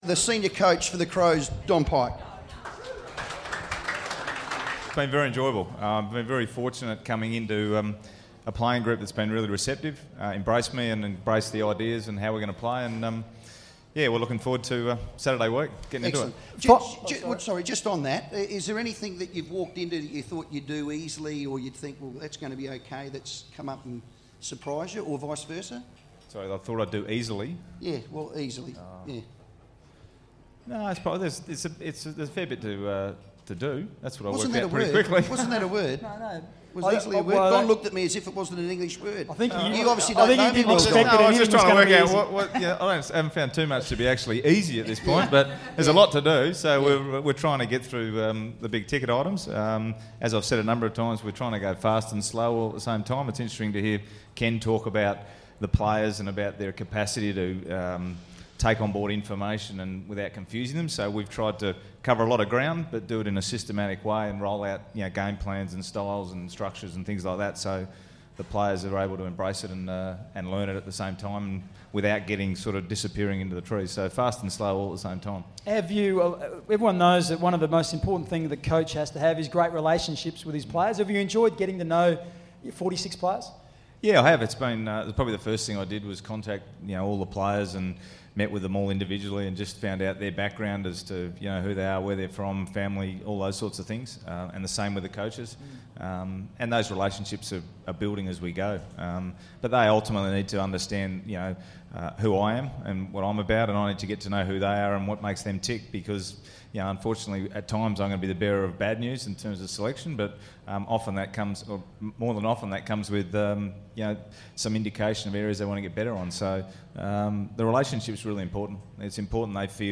Senior Coach Don Pyke was a guest speaker at the FIVEaa footy launch event